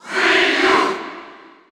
Category: Ryu (SSBU) Category: Crowd cheers (SSBU) You cannot overwrite this file.
Ryu_Cheer_French_NTSC_SSBU.ogg